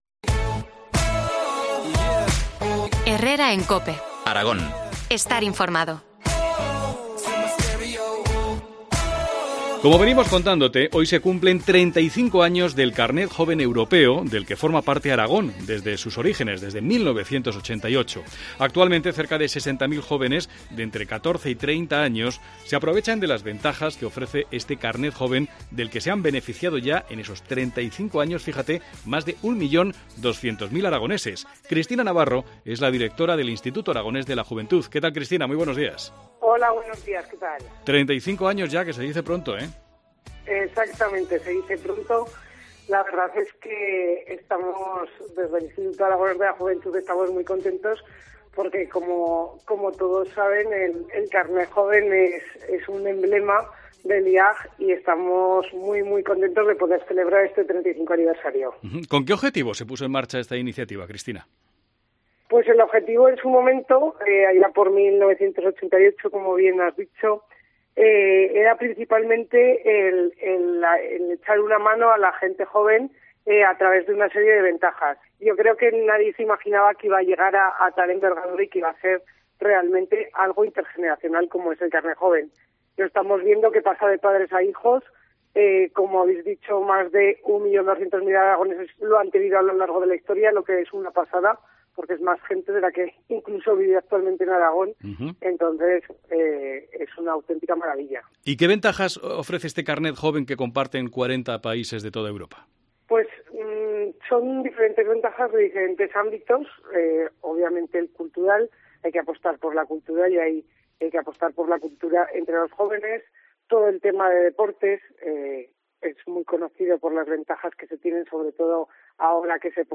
Entrevista a Cristina Navarro, directora del IAJ, sobre el 35 aniversario del Carnet Joven de Aragón